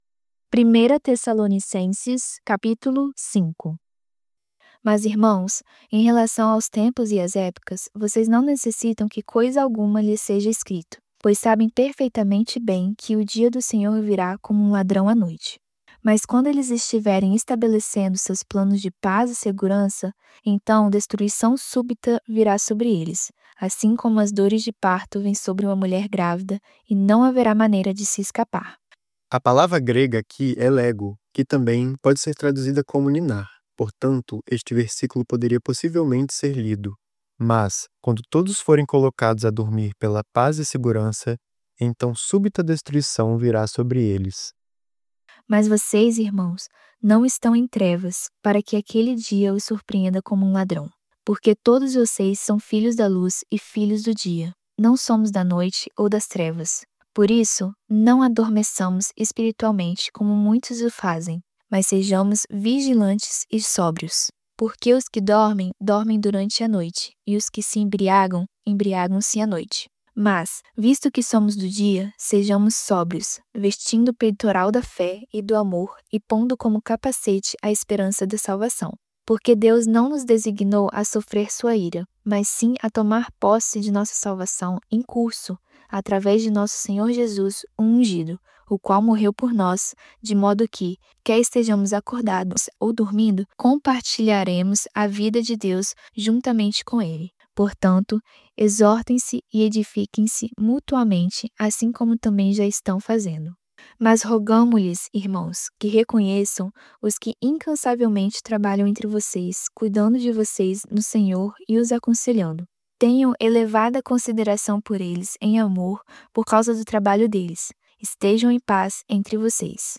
voz-audiobook-novo-testamento-vida-do-pai-primeira-tessalonicenses-capitulo-5.mp3